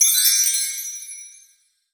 chimes_magical_bells_02.wav